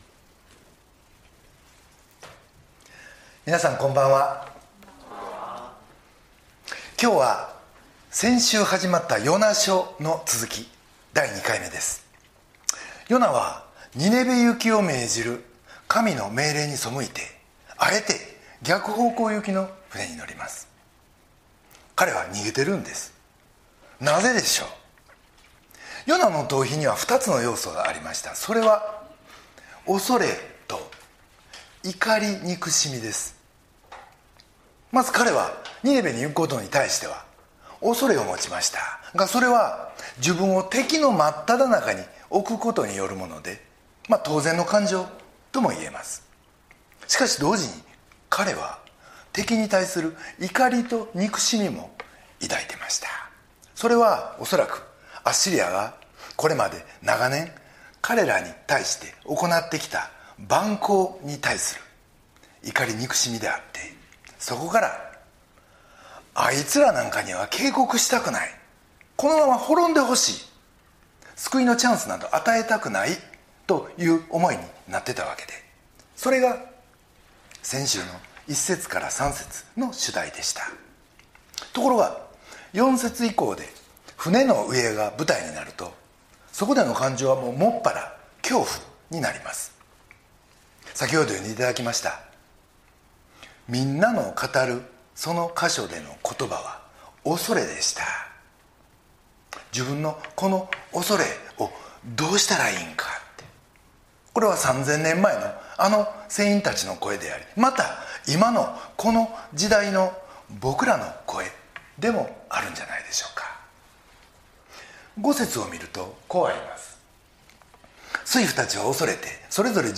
礼拝メッセージ